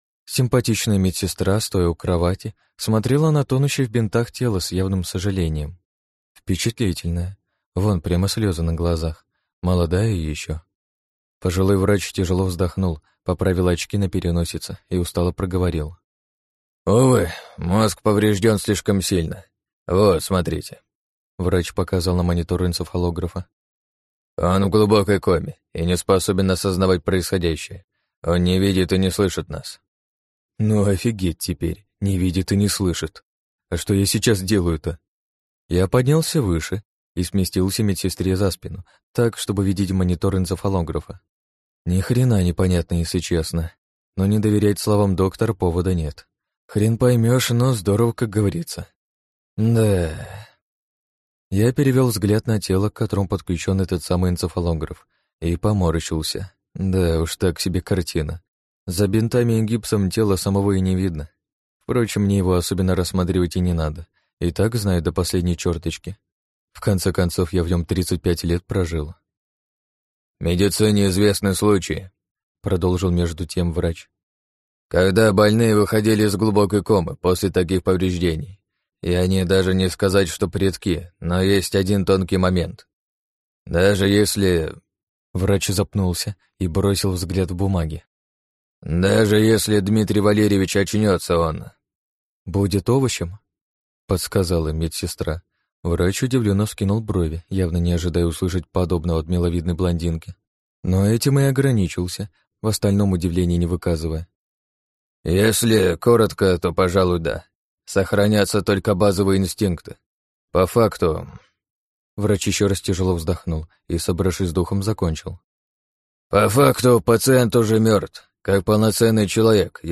Аудиокнига Гиблые земли | Библиотека аудиокниг